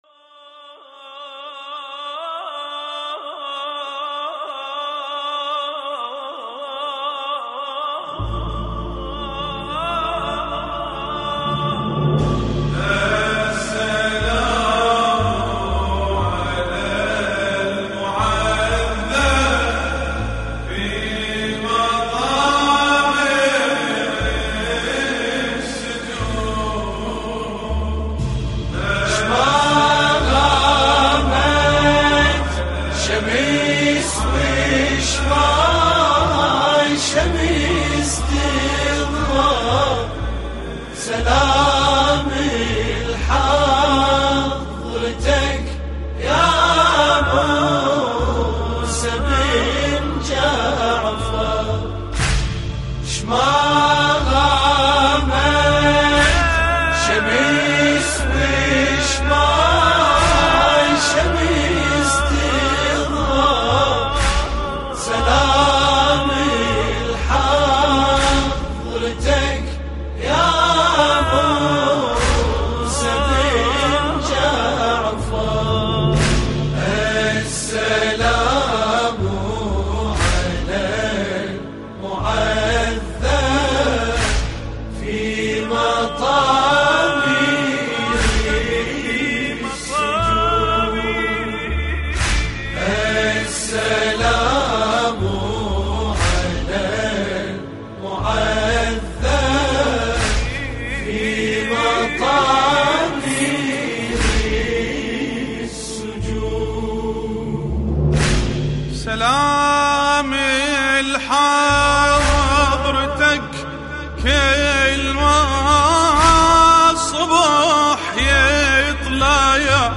مراثي الامام الكاظم (ع)